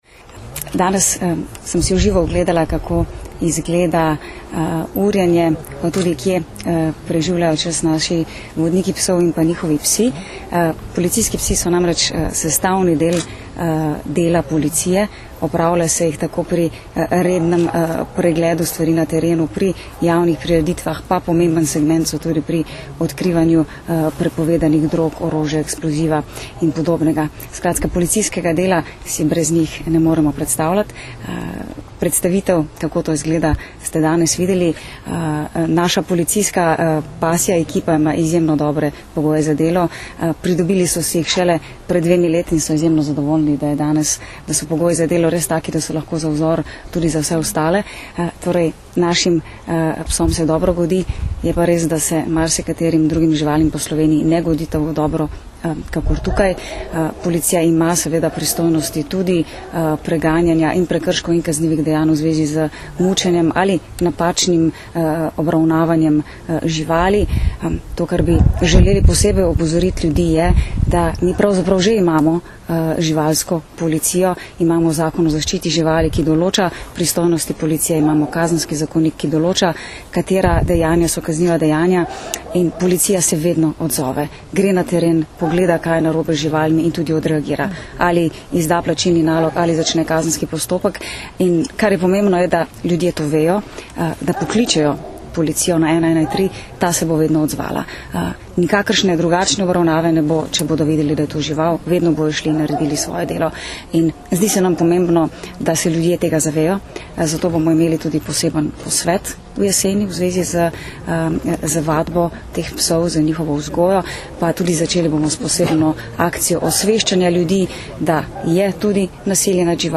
Zvočni posnetek izjave ministrice Katarine Kresal (mp3)